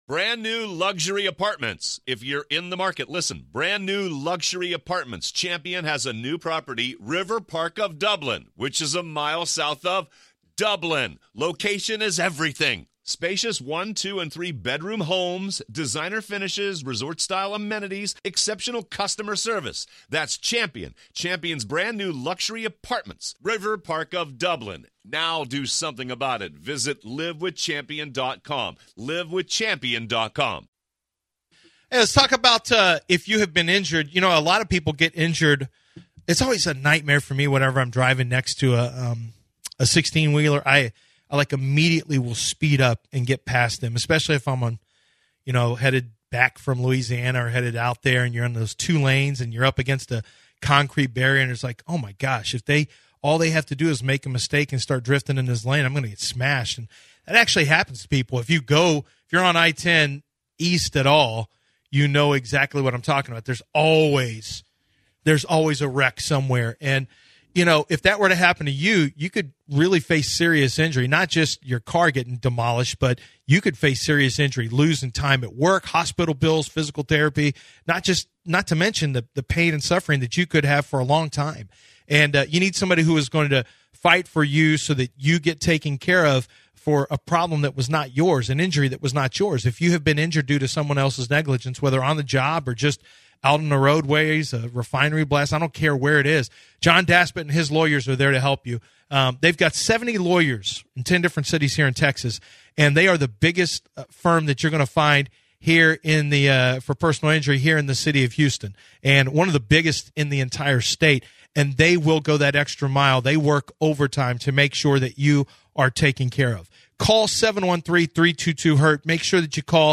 This 3rd hour covered Demeco Ryans and talking about the draft of the quarterback for the Texans. They also covered the NFL Draft and college basketball and the rockets. they finally finished it off with Jay Williams special gust speaking and then news of the weird.